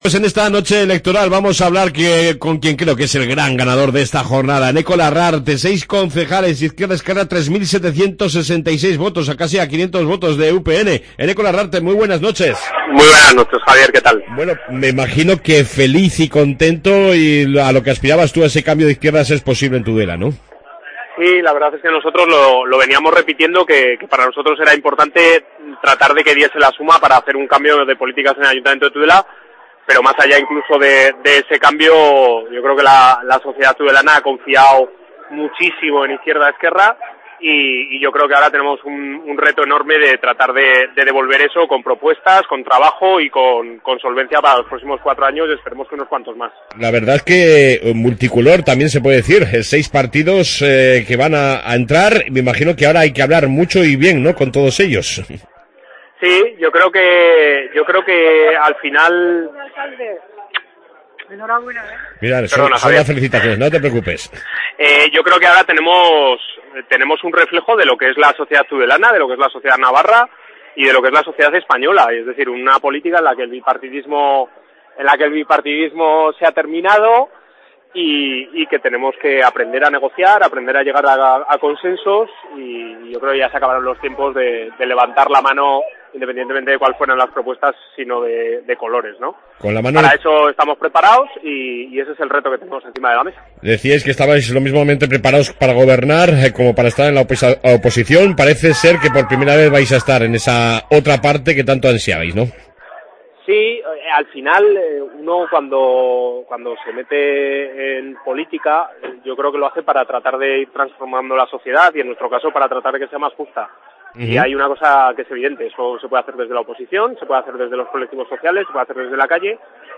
AUDIO: Palabras del que será, con permiso de otros, el futuro alcalde de Tudela Eneko larrarte